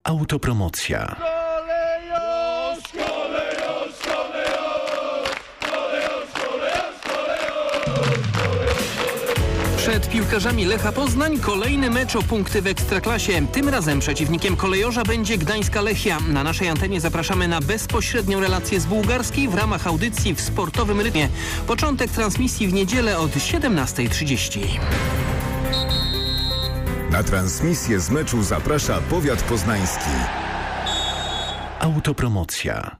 Ale też nasze klipy zapowiadające mecze Lecha są przygotowane z dużym poczuciem humory, wykorzystując gwarę czy też różnego rodzaju motywy stadionowe.